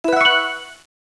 get_coin.wav